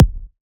edm-kick-37.wav